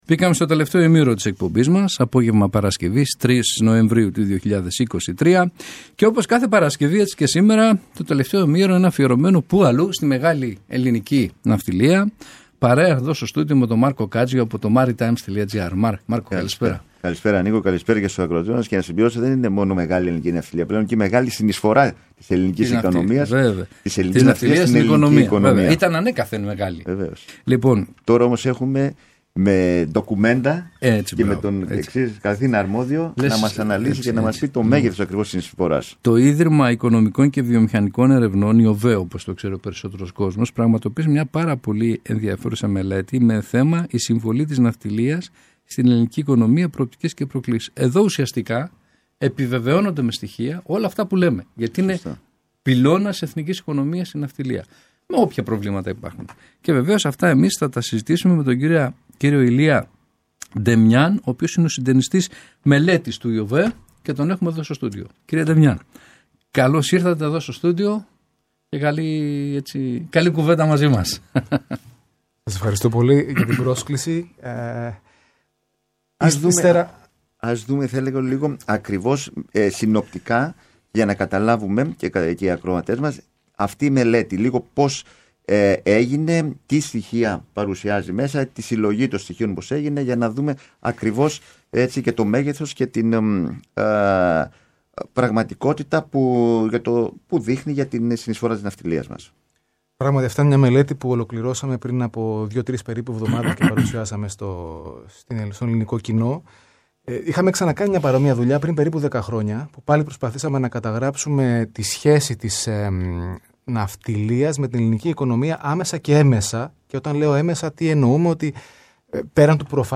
Για την προσφορά της ναυτιλίας στην ελληνική οικονομία, τις προοπτικές και προκλήσεις μίλησε στο Κανάλι Ένα